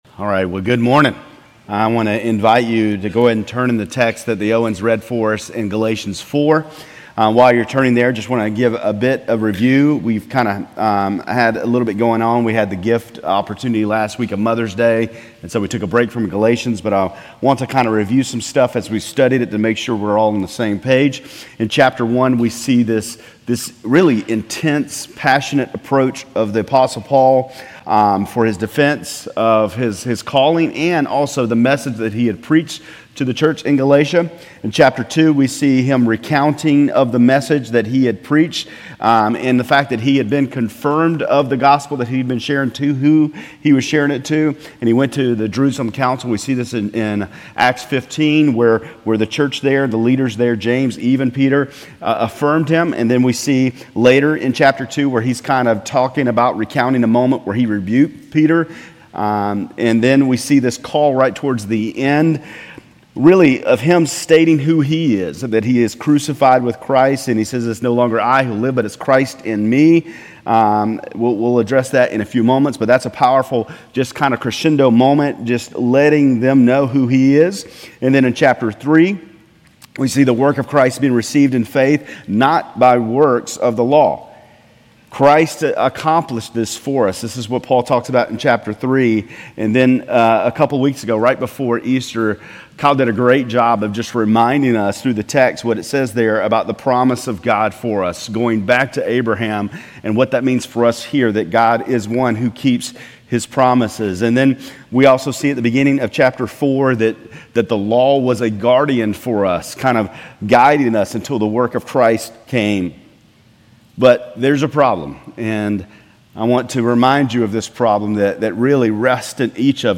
Grace Community Church Lindale Campus Sermons Galatians 4:8-31 May 20 2024 | 00:23:17 1x 00:00 / 00:23:17 Subscribe Share RSS Feed Share Link Embed